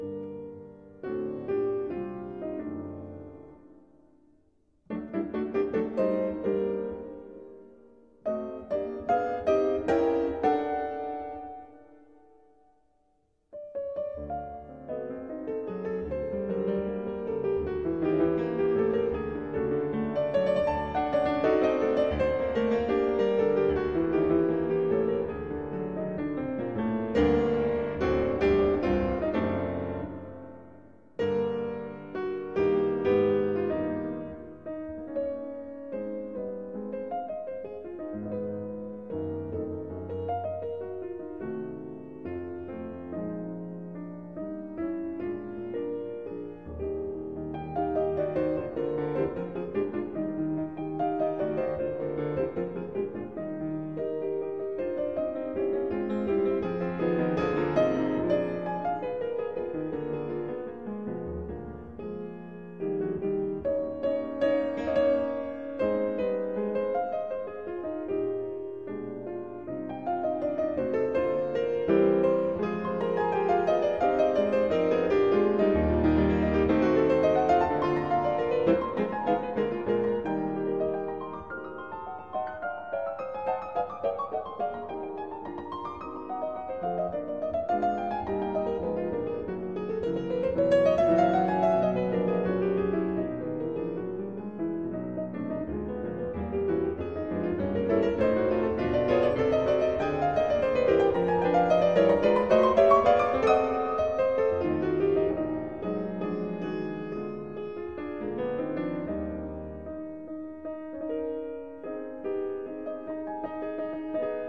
Piano sonata in G minosr
Clara-Schumann-Piano-Sonata-in-G-minor-mp3cut.net_.m4a